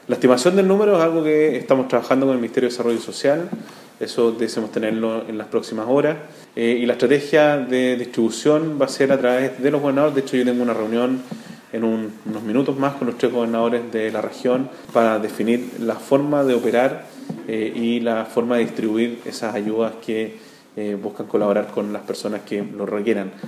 El intendente Sergio Giacaman dijo que la entrega de estas canastas familiares se coordinará a través de las gobernaciones y de los datos que pueda aportar la Seremi de Desarrollo Social.
cua-canastas-intendente.mp3